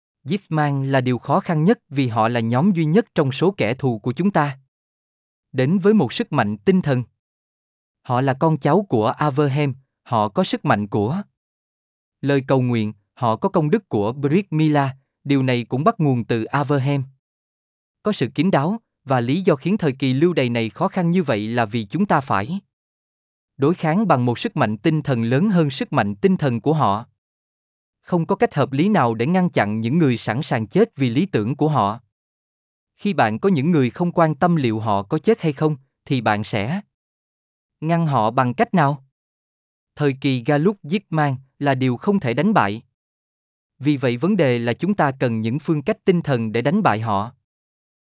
صوتية مدبلجة باللغة الفيتنامية تنقل شهادة حاخام يهودي عن المسلمين وإيمانهم.